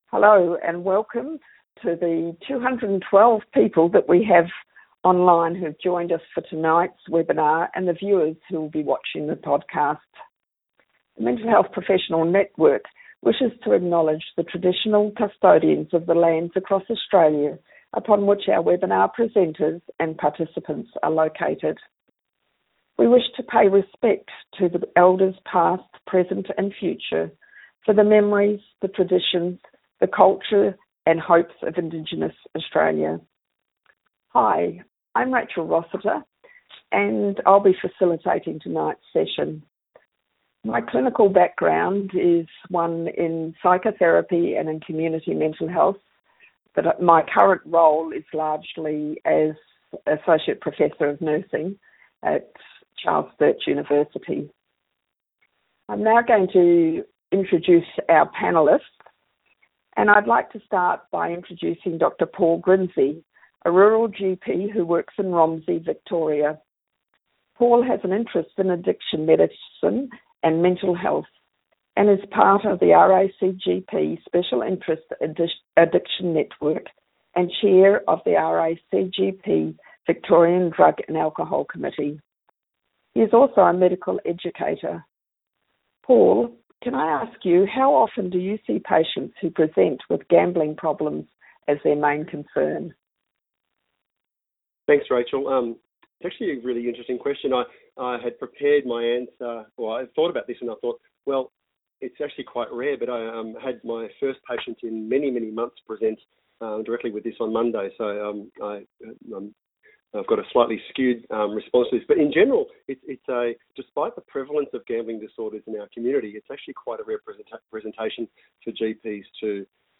Join our interdisciplinary panel of experts for a discussion about how collaborative care may support people who are affected by problem gambling.